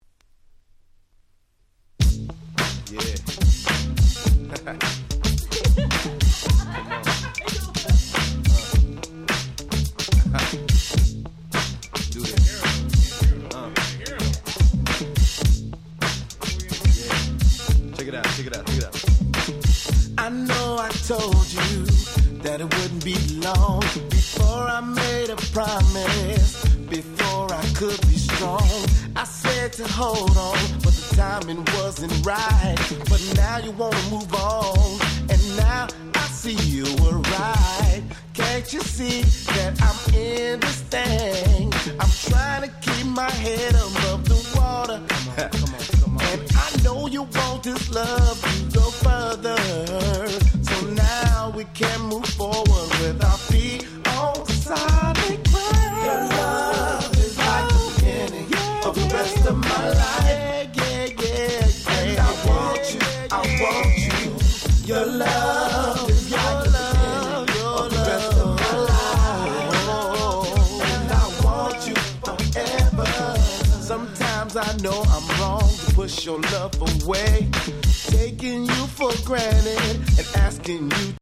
Bay Areaのマイナーゴスペルグループによるドヤバイ1発！！